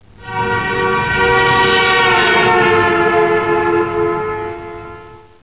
Truckhorn.wav